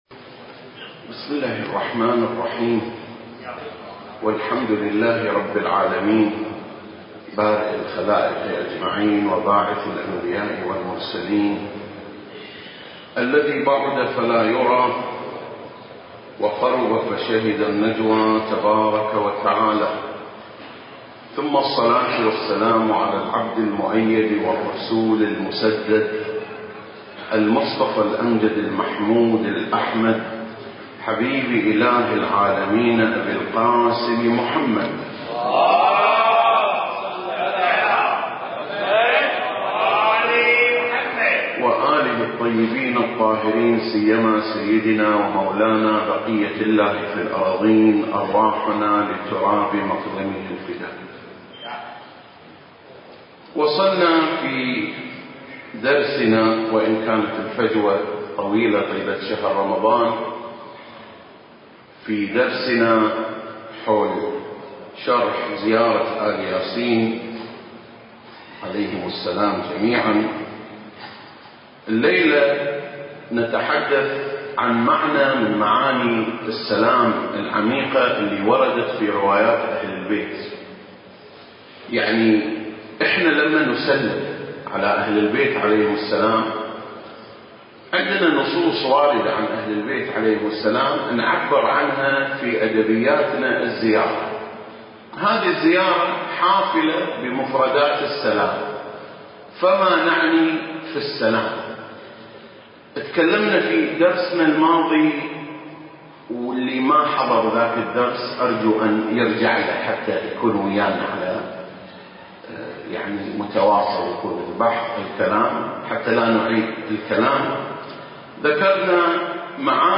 سلسلة: شرح زيارة آل ياسين (19) المكان: مسجد مقامس - الكويت التاريخ: 2021